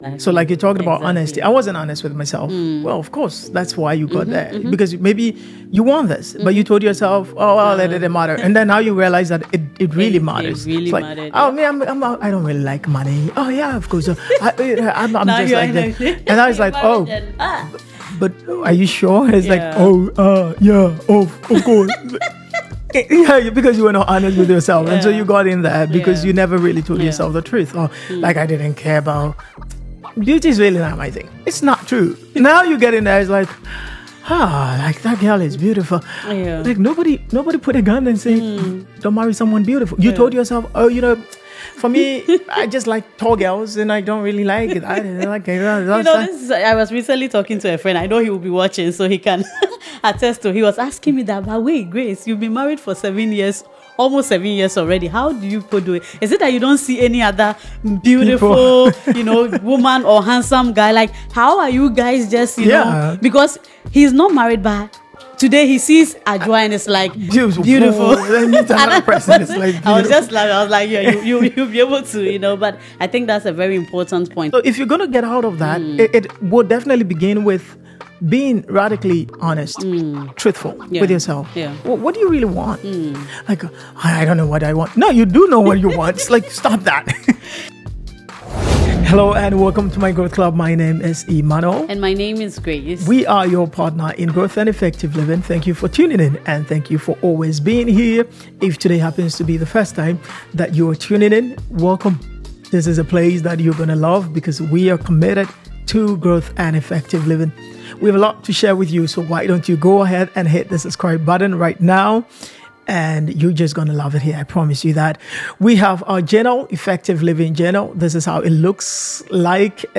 We dive into the importance of being radically honest with yourself about what you truly want and why. The conversation explores how our choices and lack of self-honesty can lead to feeling stuck.